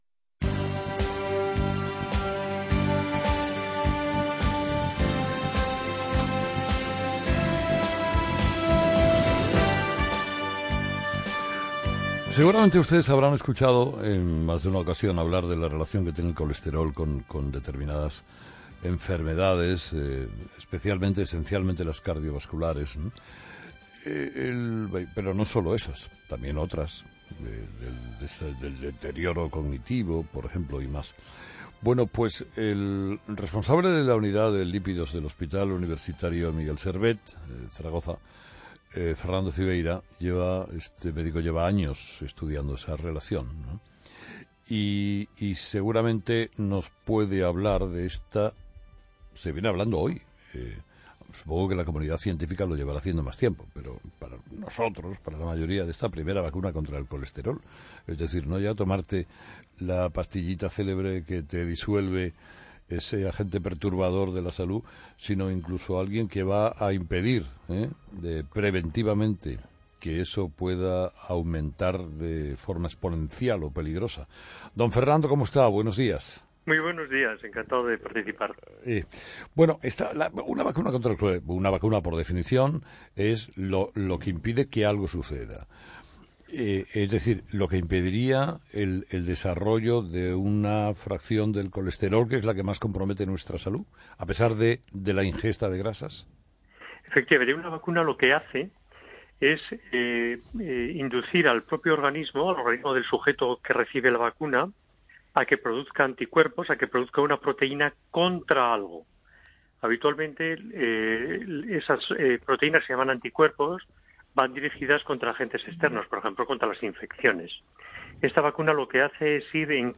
Últimas Noticias/Entrevistas